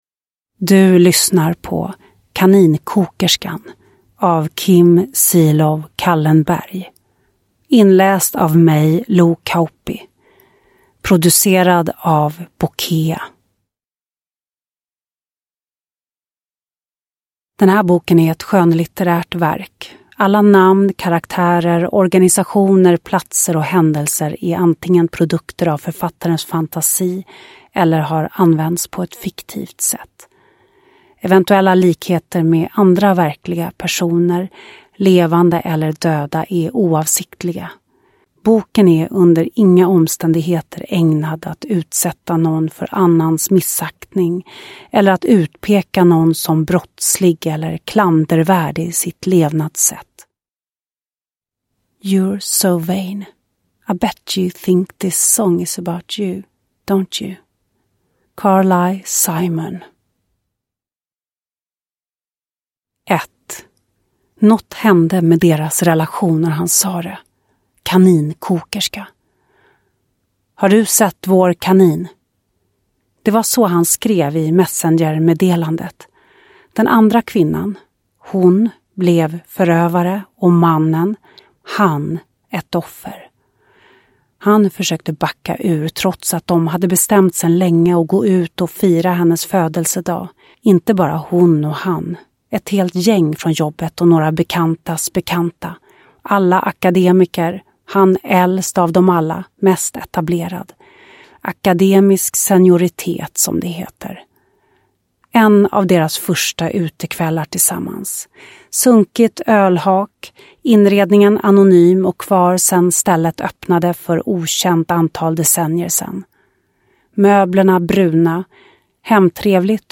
Uppläsare: Lo Kauppi
Ljudbok